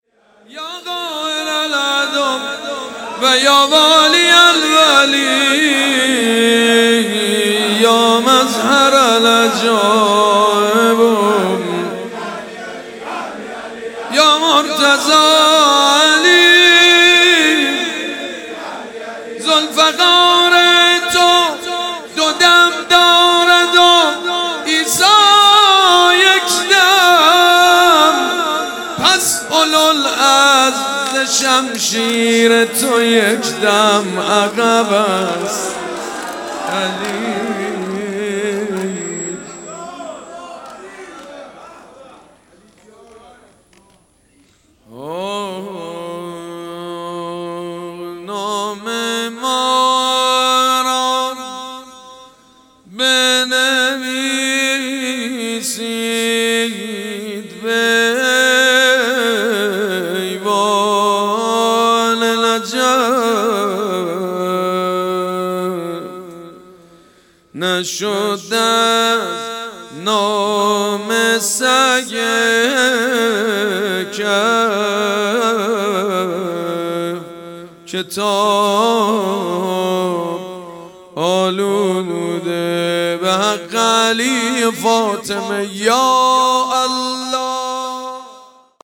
حاج سيد مجید بنی فاطمه
شب اول فاطمیه 95 - هيئت ثار الله - مدح - ذوالفقار تو دو دم دارد